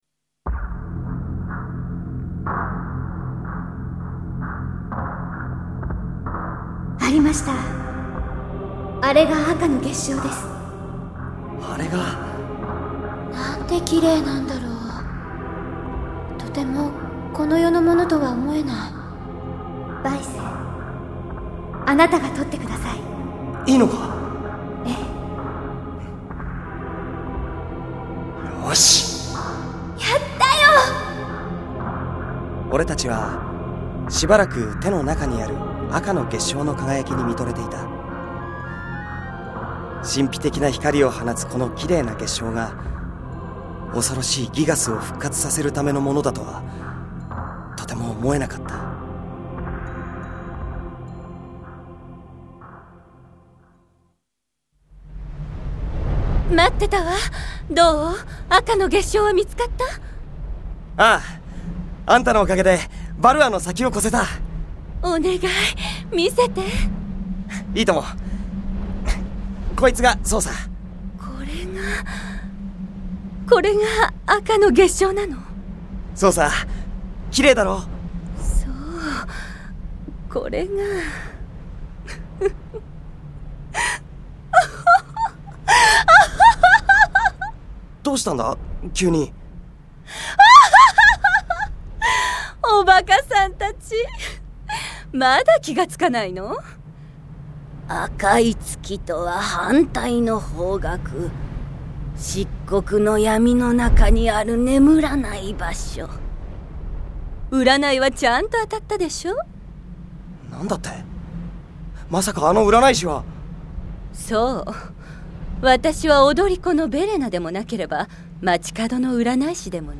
CD Drama